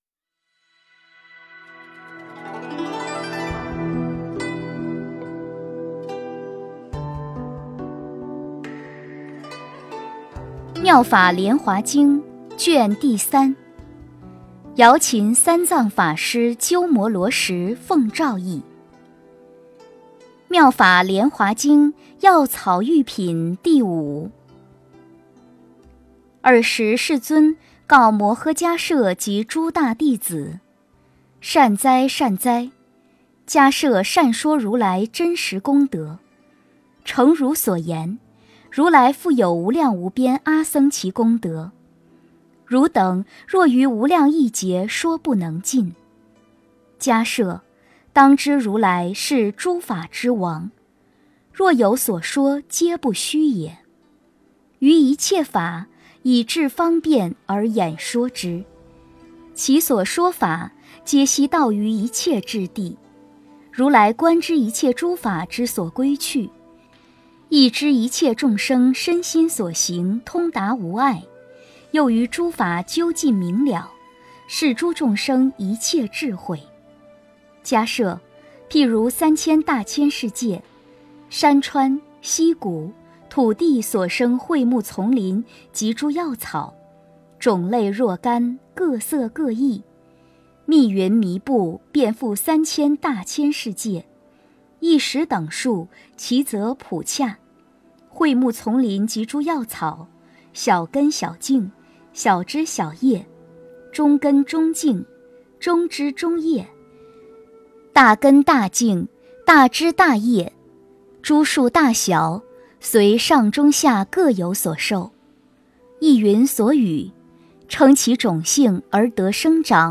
《妙法莲华经》药草喻品第五 - 诵经 - 云佛论坛